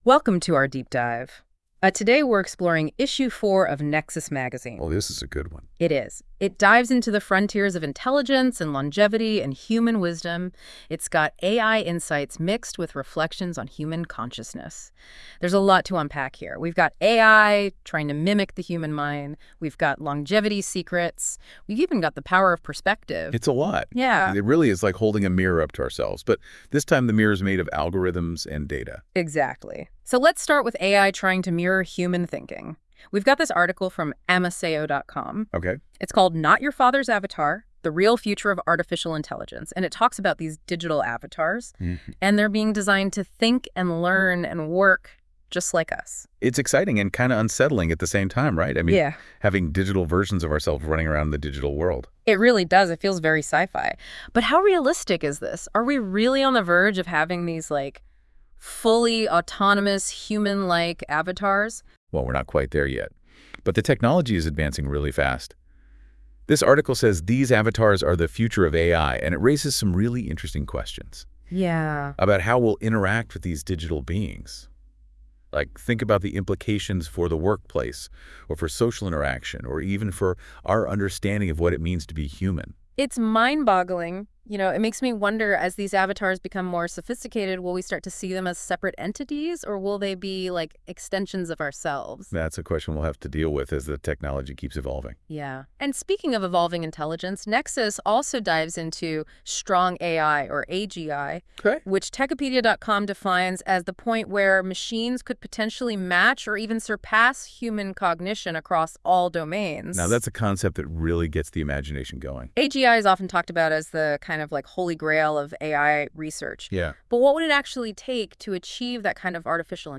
Nexus Deep Dive is an AI-generated conversation in podcast style where the hosts talk about the content of each issue of Nexus: Exploring the Frontiers of Intelligence .